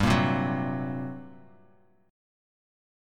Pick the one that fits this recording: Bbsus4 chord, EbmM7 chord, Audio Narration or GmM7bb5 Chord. GmM7bb5 Chord